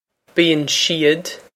Pronunciation for how to say
bee-on she-od
This is an approximate phonetic pronunciation of the phrase.